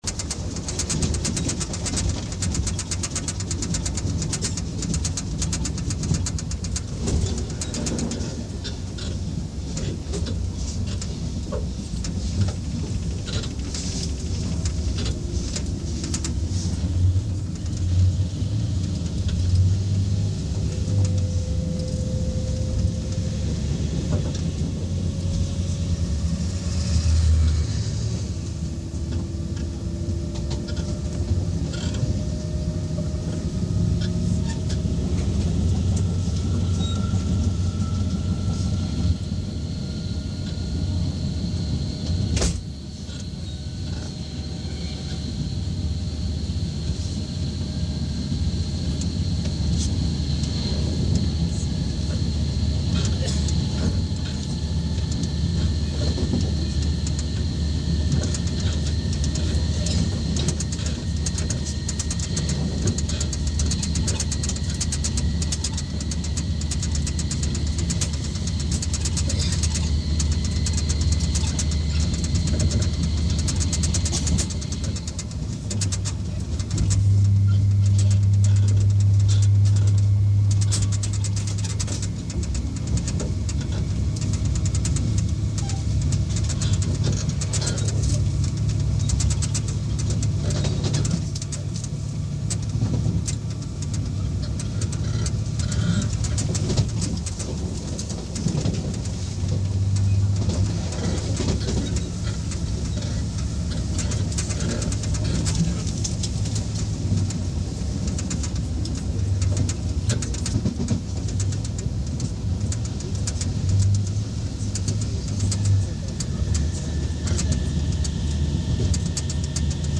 車内ターボ音
中庄-庭瀬徐行後のフル加速（下り）
２箇所の徐行区間のフルブレーキ、フル加速
nakashouslowdown.mp3